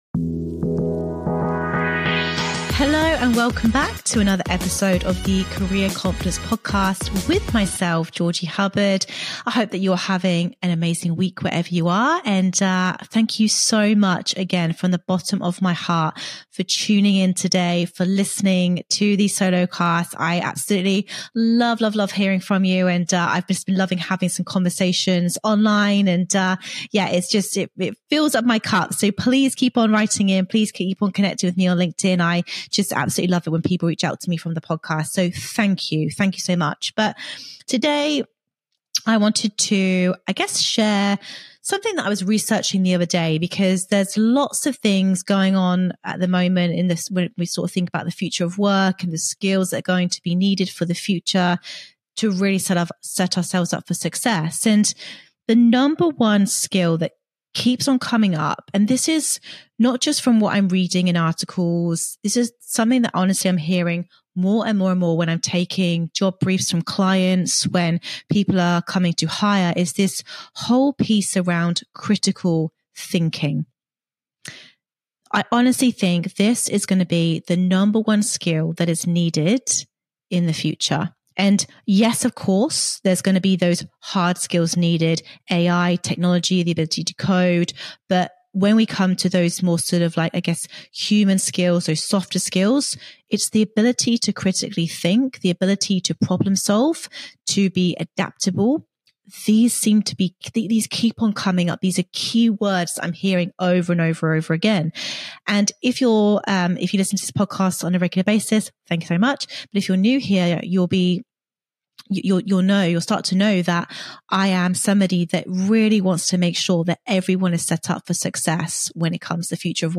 In today's solo episode